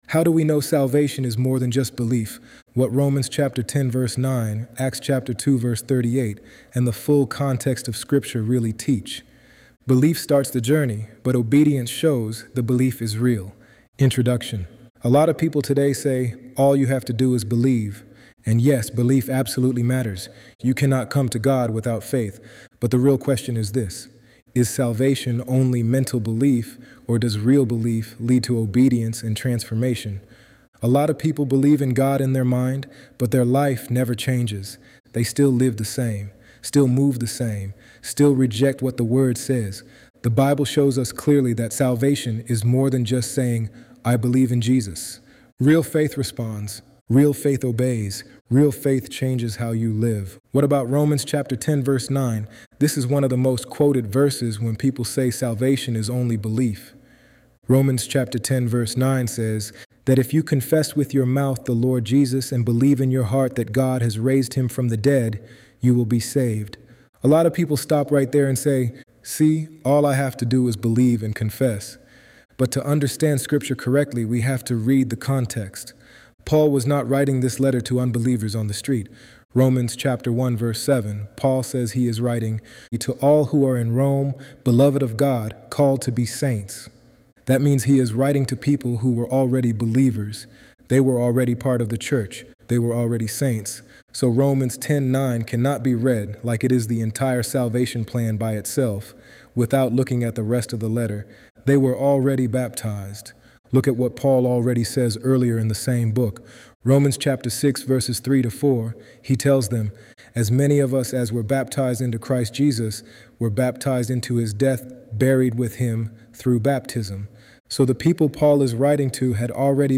ElevenLabs_Untitled_project-1.mp3